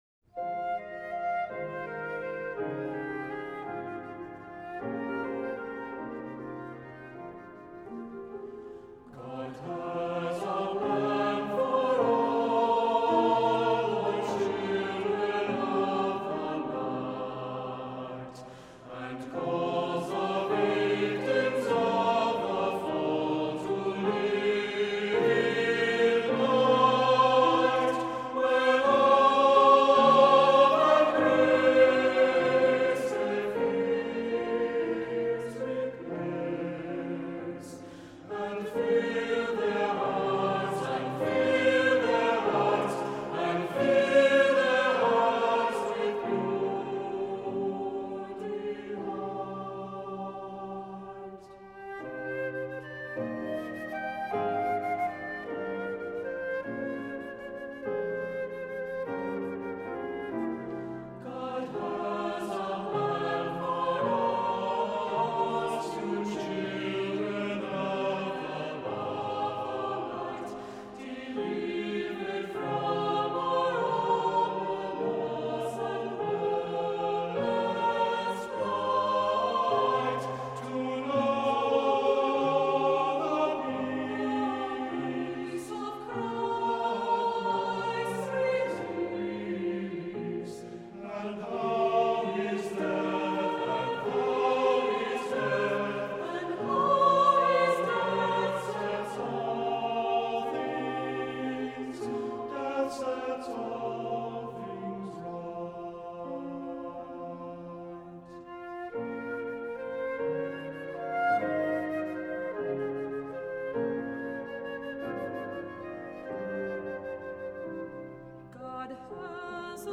Voicing: Unison; Two-part mixed